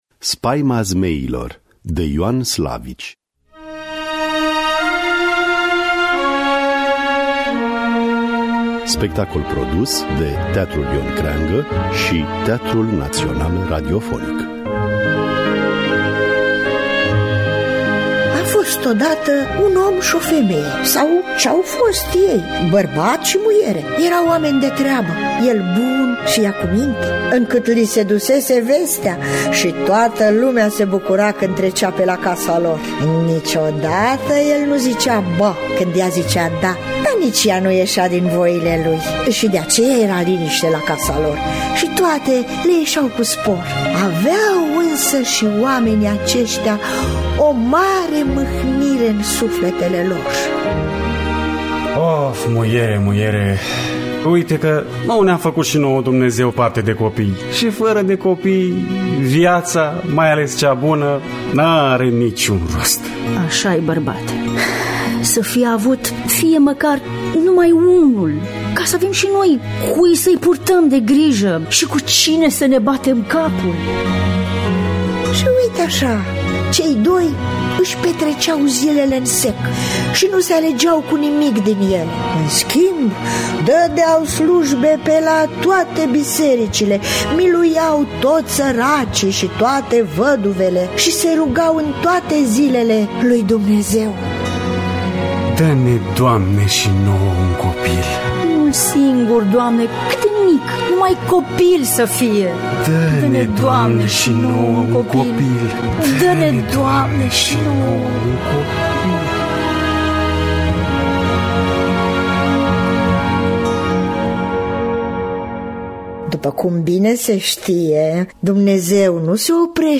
Dramatizarea radiofonică şi regia artistică